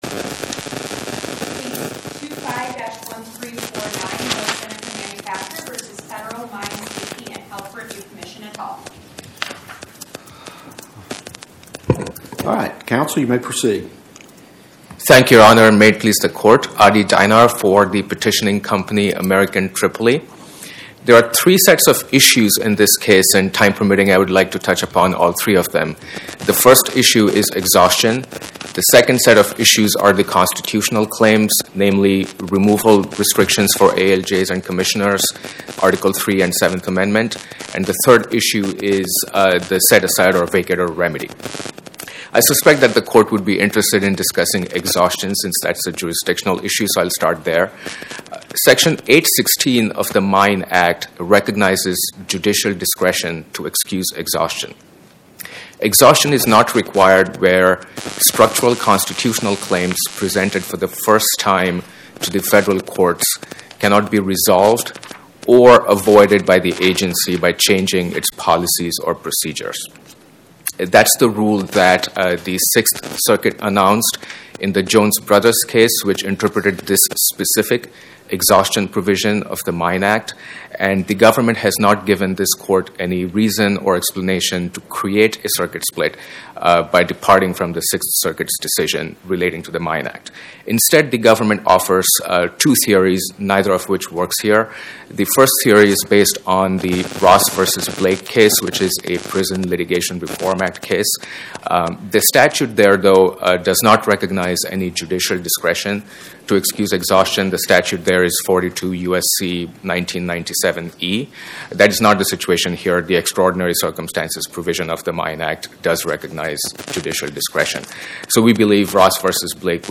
The court heard oral arguments today on whether American Tripoli was entitled to a trial by jury in a real court.